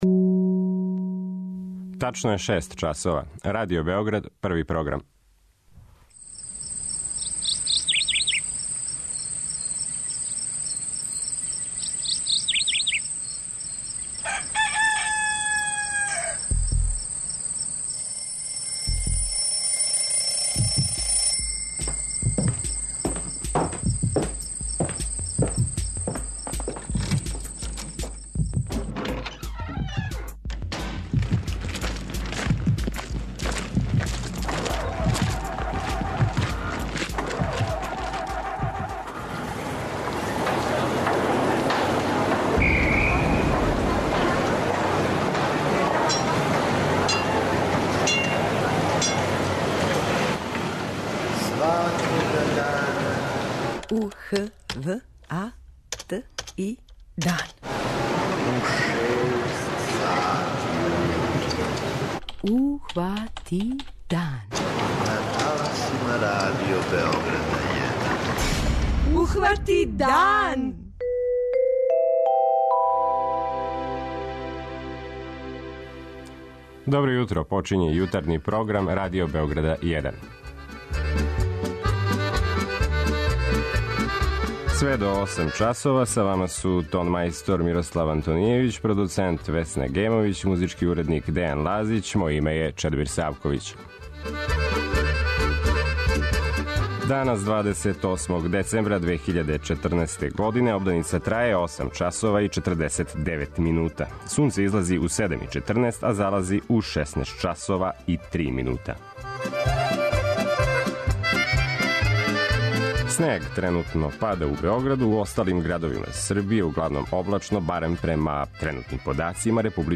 преузми : 57.30 MB Ухвати дан Autor: Група аутора Јутарњи програм Радио Београда 1!